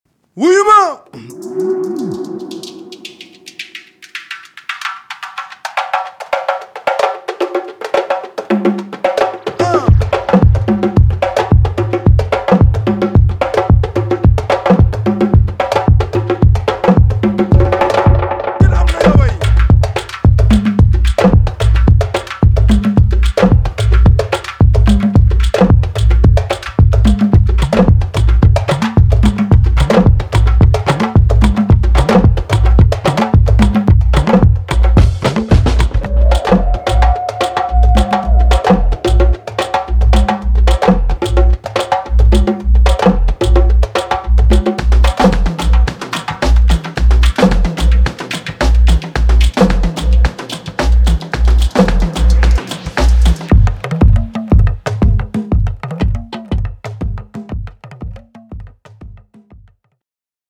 who played sabar and tama drums.
bass